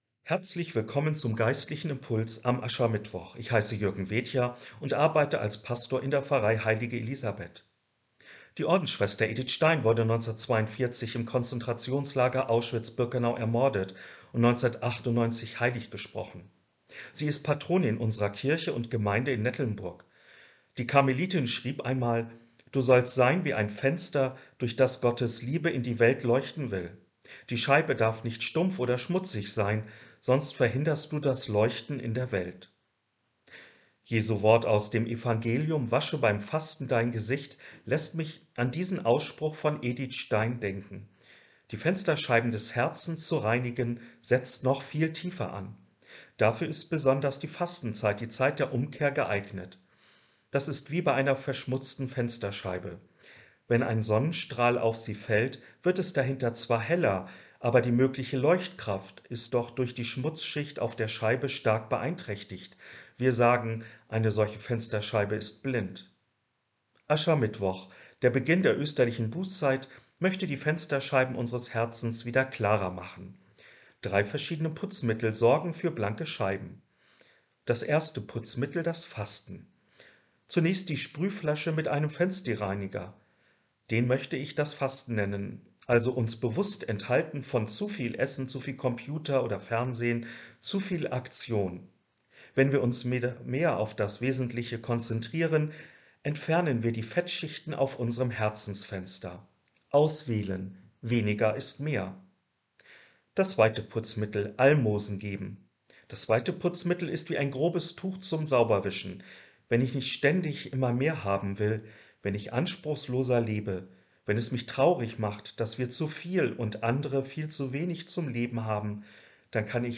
Geistlicher Impuls zum Aschermittwoch am 2. März 2022